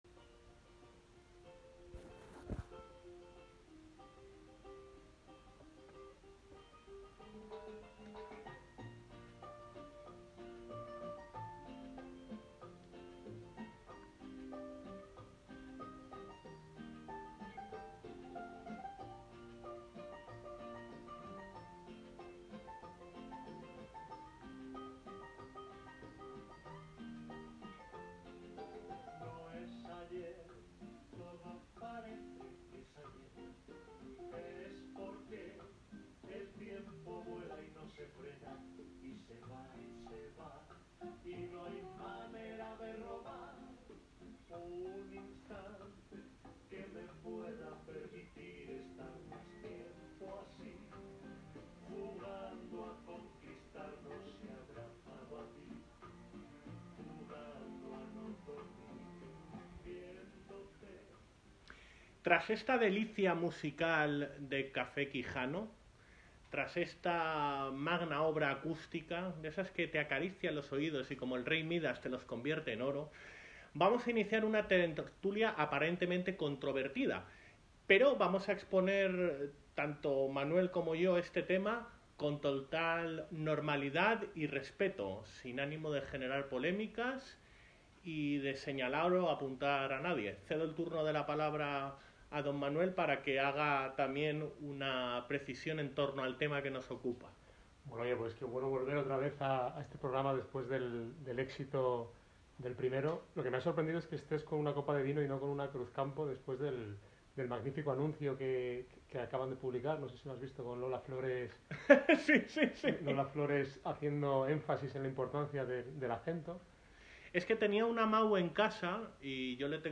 Dos católicos disertan sobre los peligros que encierran las prácticas “new age”, como el yoga, reiki, mindfulness y similares.